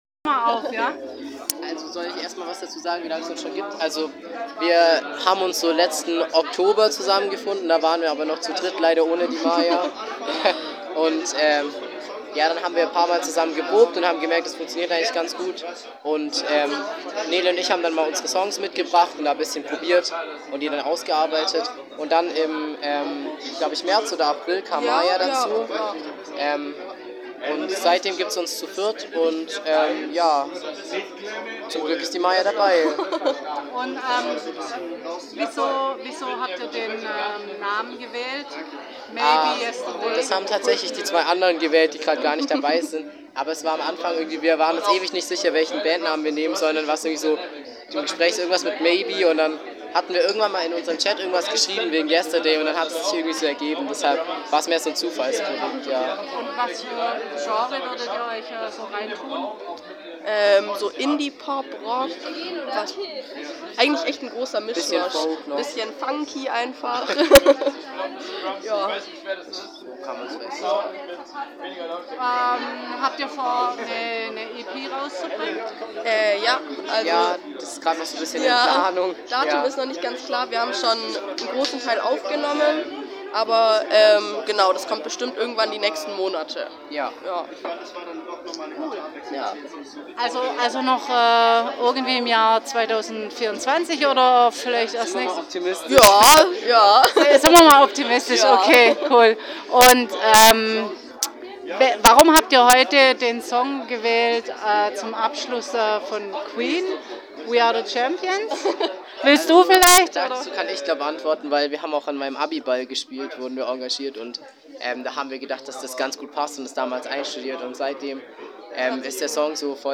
kleines Interview mit Maybe Yesterday beim Newcomer Konzert 26.9.24